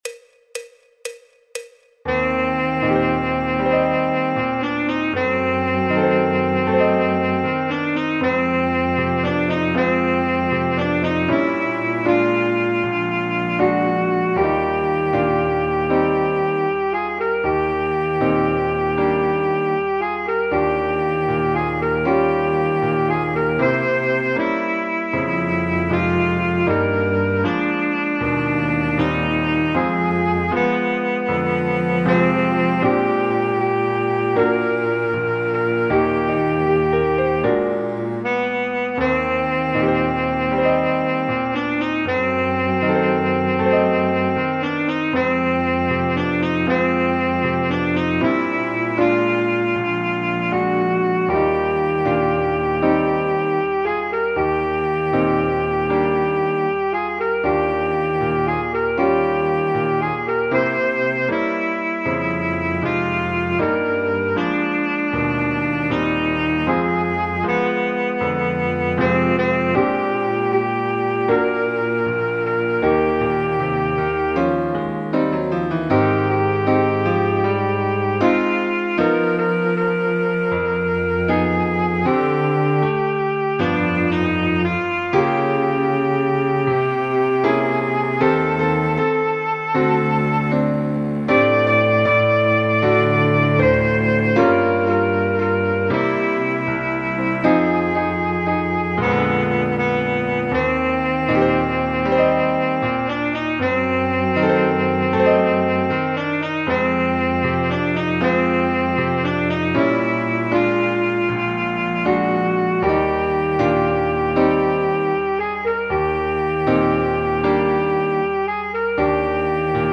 El MIDI tiene la base instrumental de acompañamiento.
Saxofón Alto / Saxo Barítono
Jazz, Popular/Tradicional